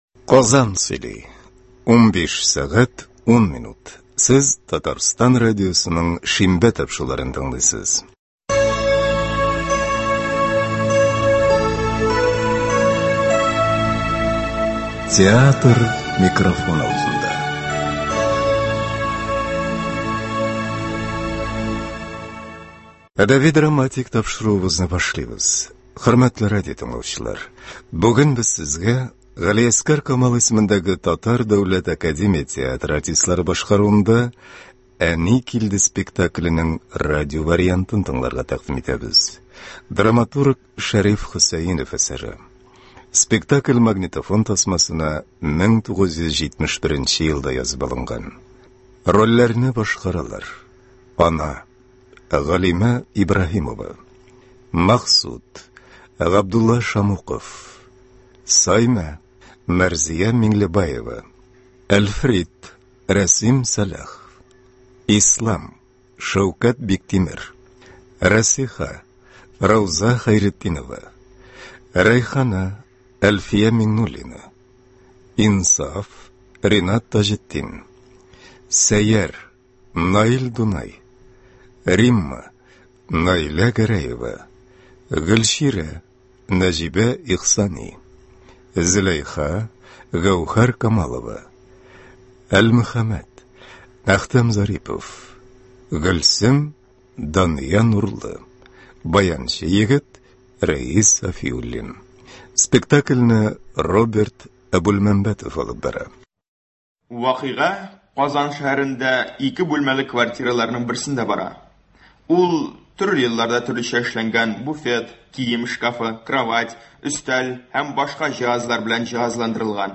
Г.Камал ис.ТДАТ спектакленең радиоварианты. 1 нче өлеш.
Игътибарыгызга Г.Камал исемендәге Татар Дәүләт академия театры артистлары башкаруында “Әни килде” спектакленең радиовариантын тәкъдим итәбез.
1971 елда Татарстан радиосы студиясендә магнитофон тасмасына язып алынган